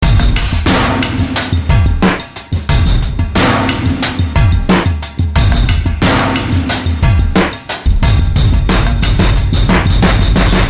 描述：这个凹槽非常坚固
标签： 90 bpm Crunk Loops Drum Loops 1.79 MB wav Key : Unknown
声道立体声